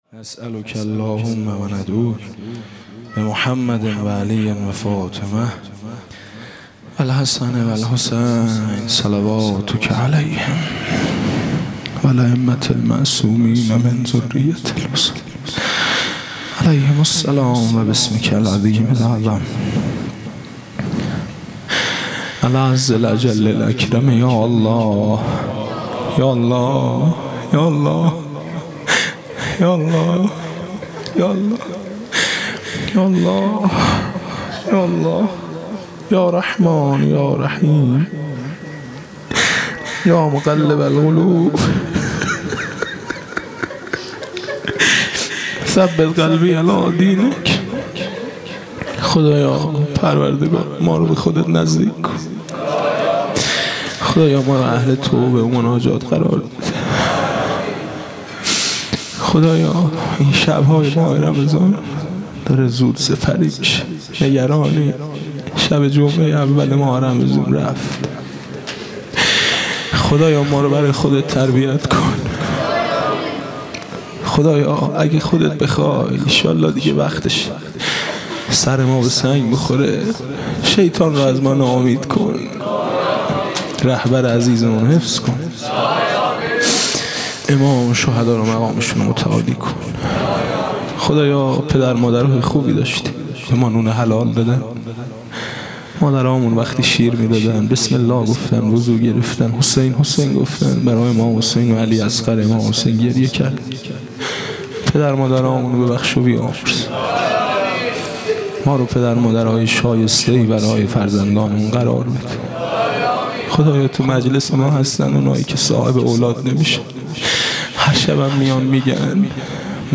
دعای پایانی